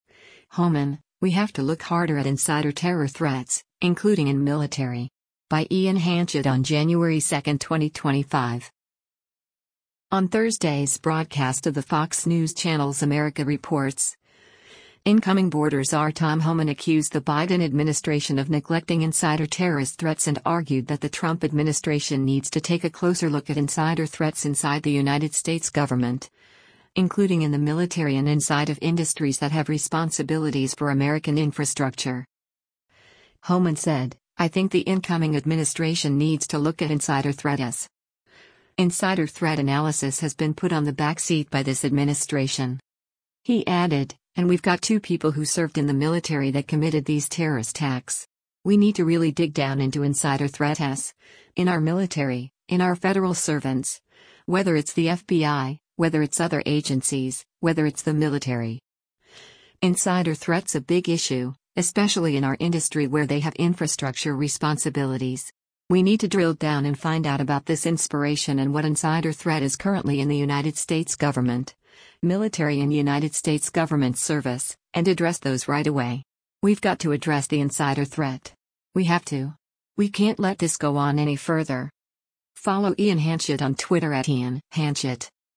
On Thursday’s broadcast of the Fox News Channel’s “America Reports,” incoming Border Czar Tom Homan accused the Biden administration of neglecting insider terrorist threats and argued that the Trump administration needs to take a closer look at insider threats inside the United States government, including in the military and inside of industries that have responsibilities for American infrastructure.